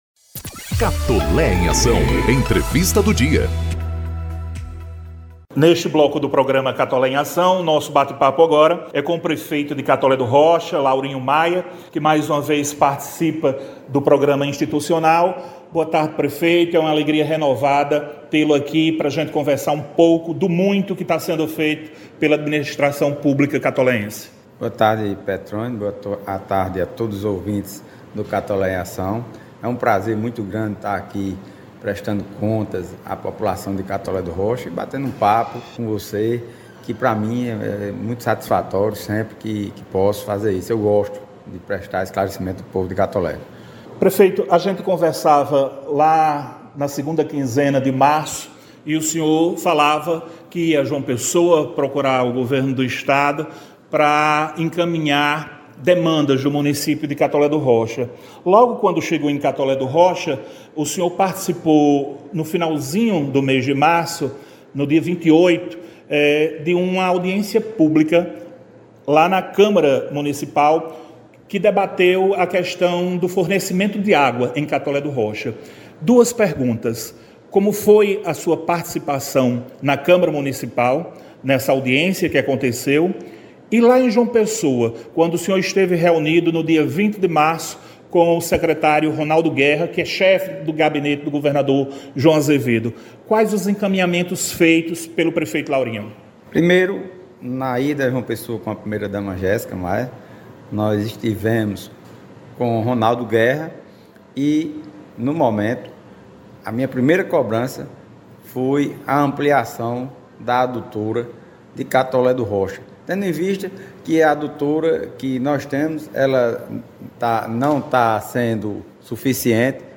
O prefeito de Catolé do Rocha, Laurinho Maia, participou da edição n° 151 do programa “Catolé em Ação” e trouxe boas notícias para a população.
Entrevista-Pref.-Laurinho-Maia.ogg